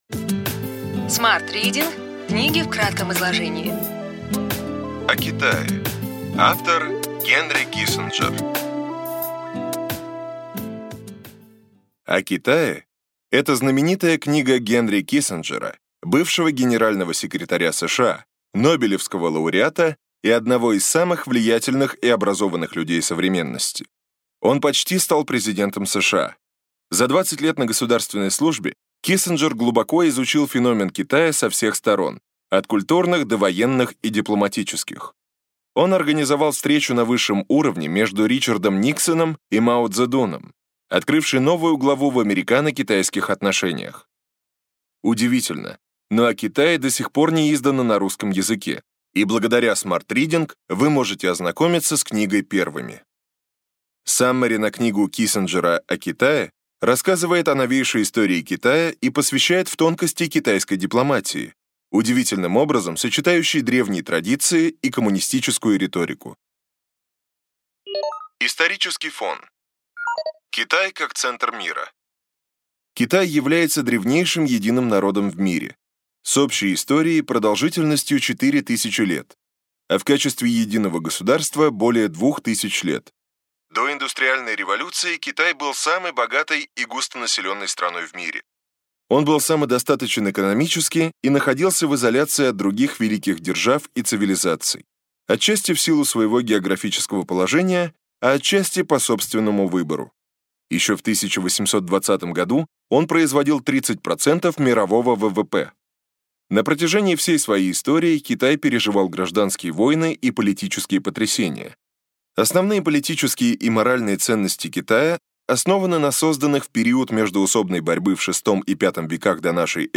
Аудиокнига Ключевые идеи книги: О Китае.